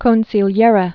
(kōnsē-lyĕrĕ)